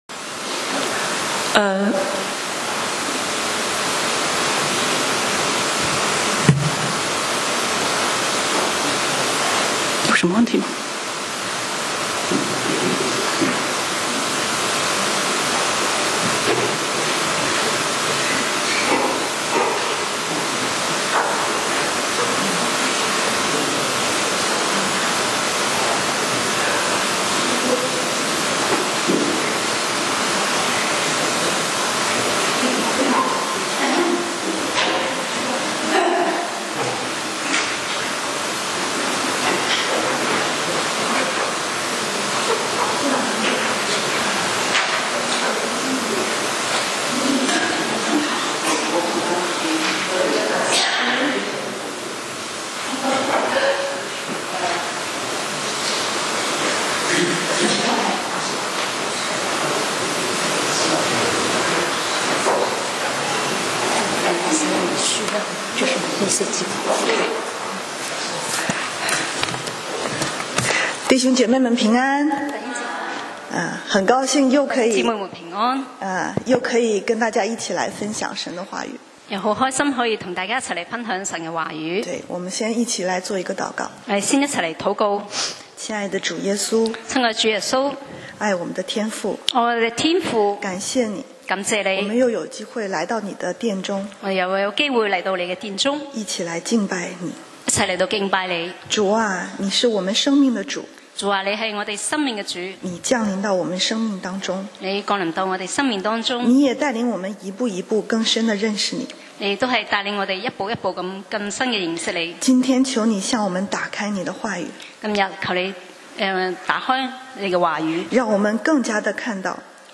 講道 Sermon 題目：當生命之主臨到 經文 Verses：约翰福音11：1-44. 1有一個患病的人、名叫拉撒路、住在伯大尼、就是馬利亞和他姐姐馬大的村莊。